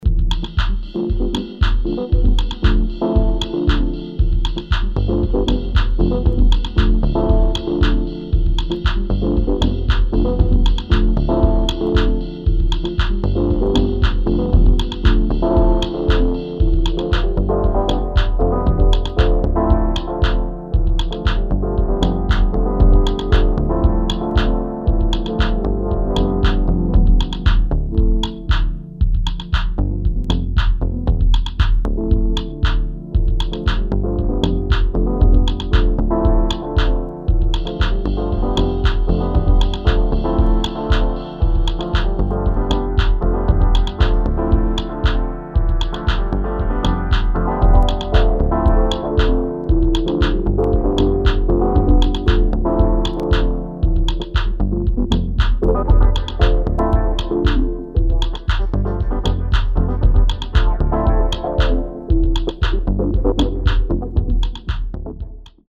[ TECHNO / MINIMAL / ACID / HOUSE ]